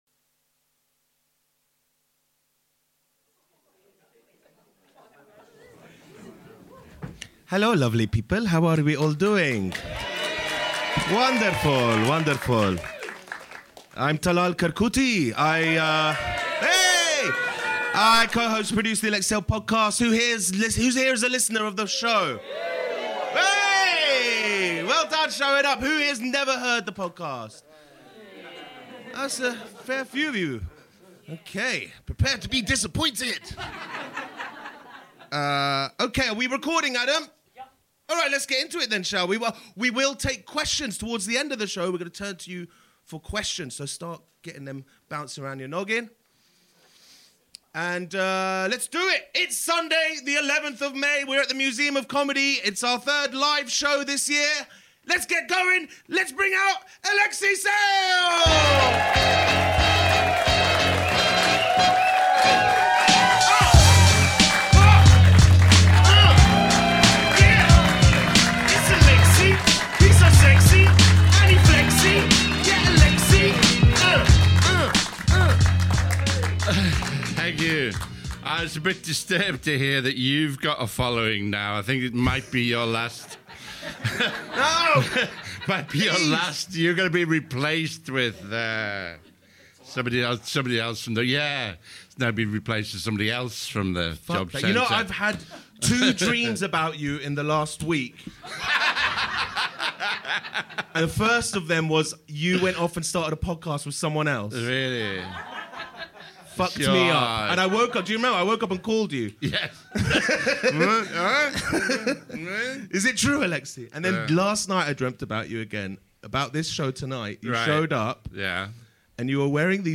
Lies! Lies! (LIVE AT THE MUSEUM OF COMEDY)